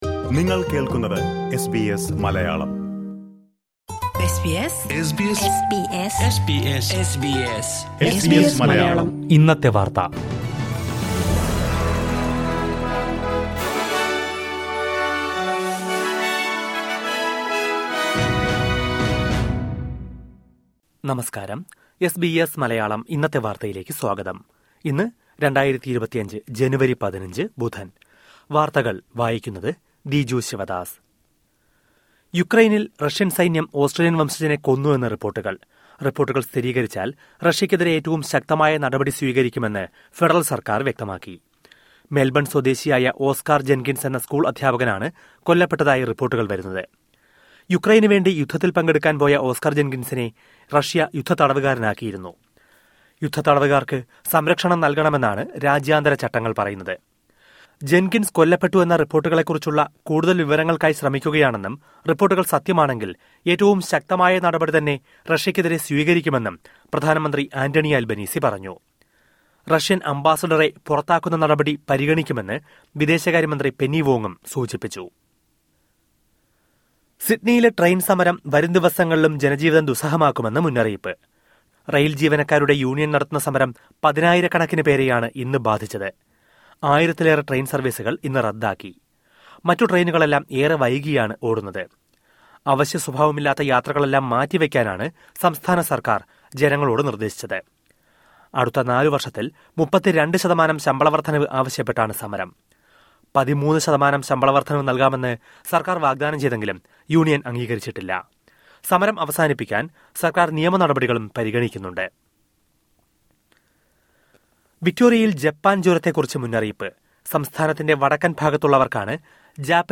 2025 ജനുവരി 15ലെ ഓസ്‌ട്രേലിയയിലെ ഏറ്റവും പ്രധാന വാര്‍ത്തകള്‍ കേള്‍ക്കാം...